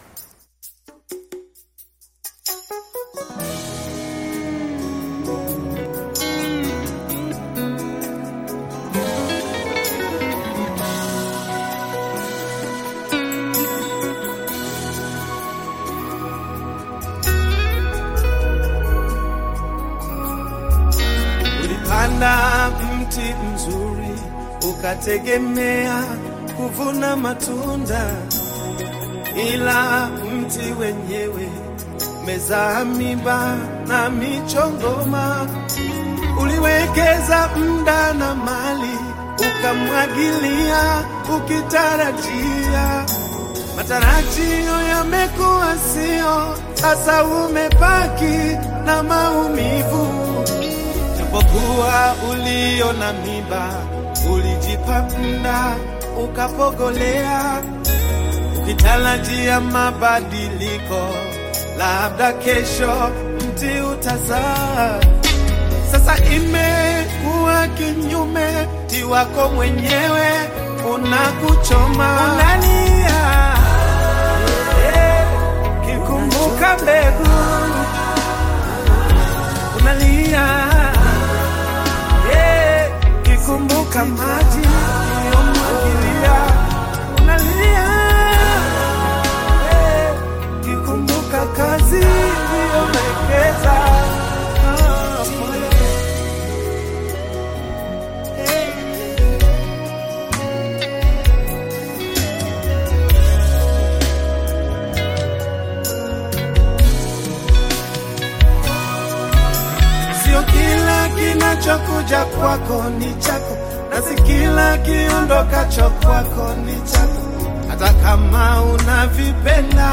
Tanzanian gospel singer and songwriter
gospel song